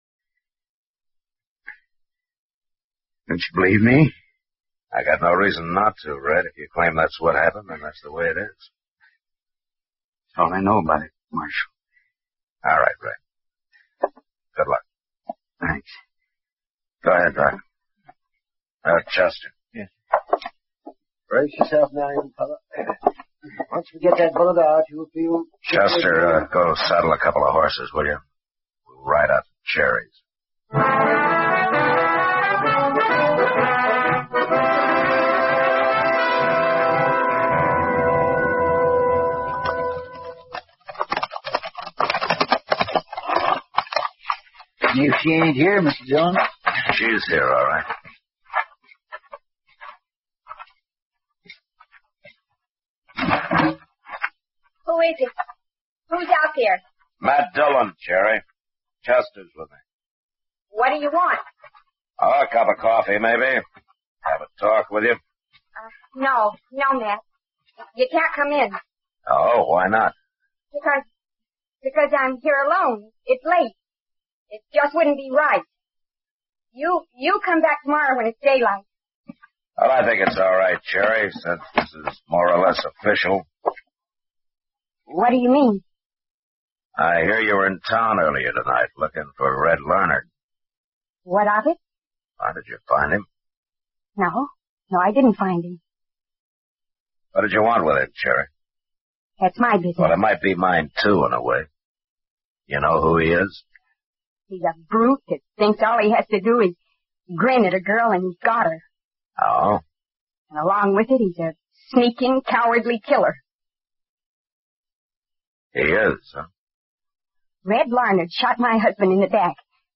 Radio station
Please enjoy the 24/7 free stream of outstanding classic old time radio programs.